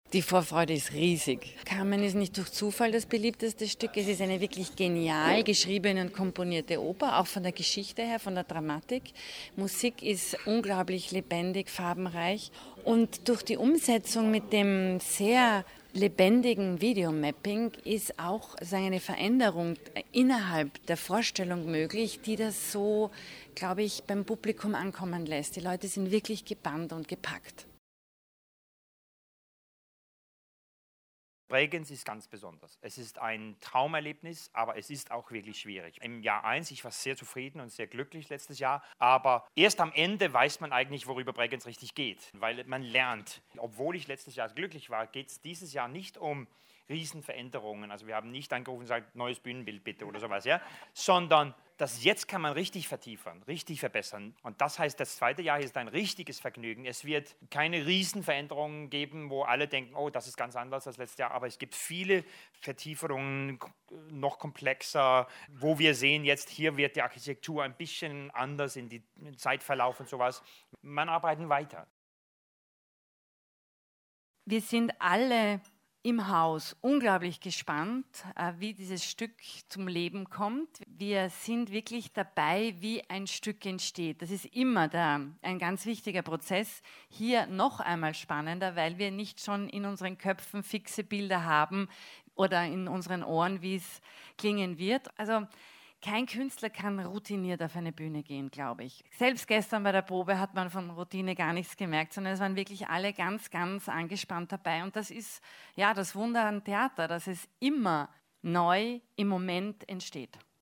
O-Ton Pressetag Feature